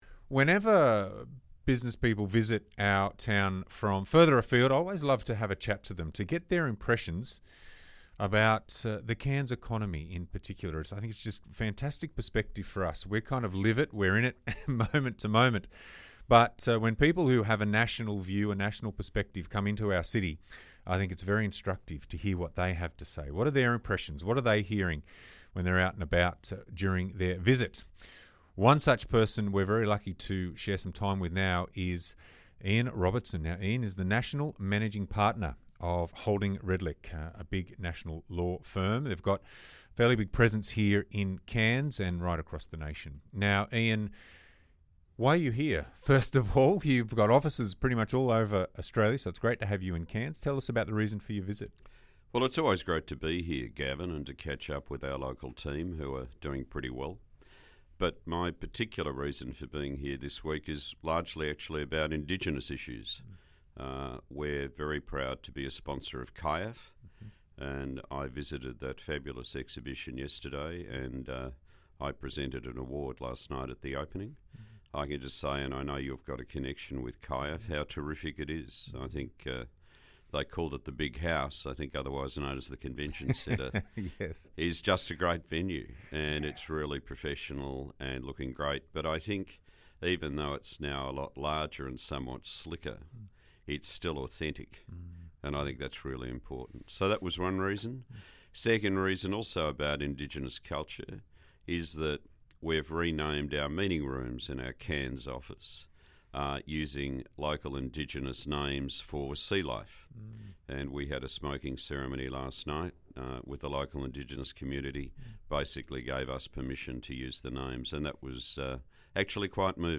A wide-ranging interview